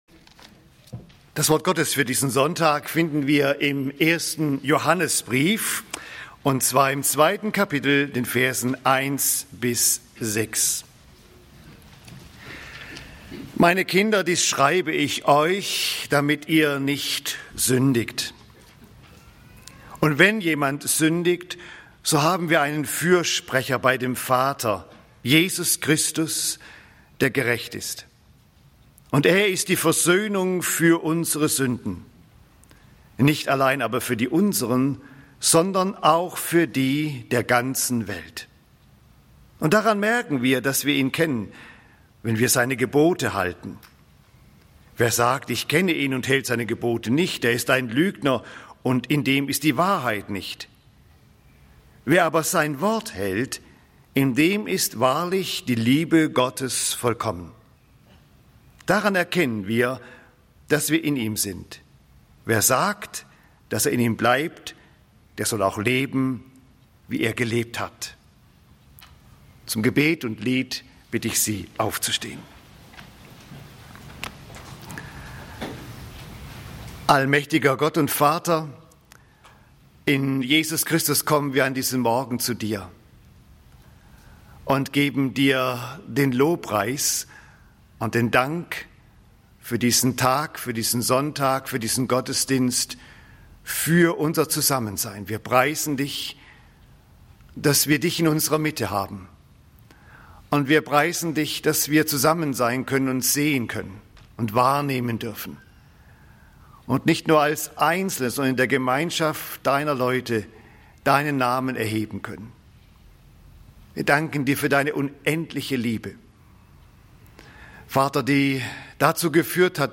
Die Liebe Gottes ist keine Einbahnstraße (1.Joh. 2, 1-6) - Gottesdienst